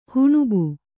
♪ huṇube